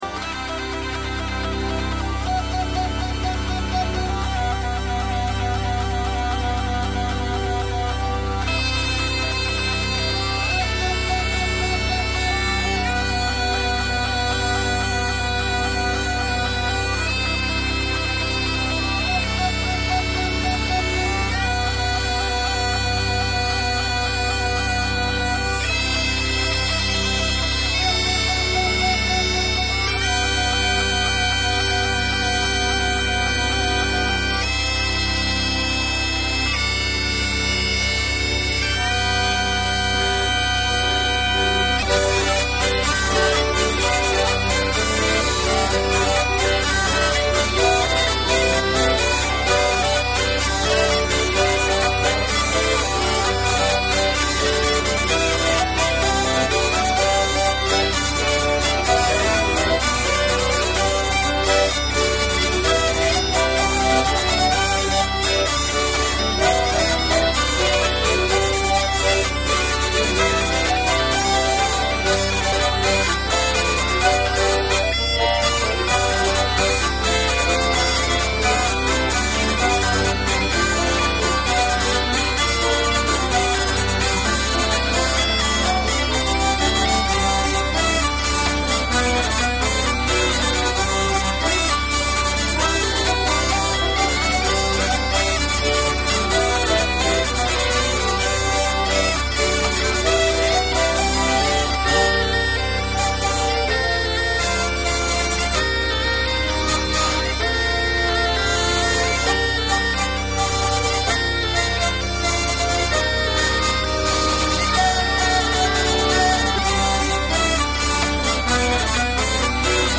Répétition du 03/06/2011 - Module 3 - Bretagne/Dauphiné/Auvergne
b2tps folkloriade.mp3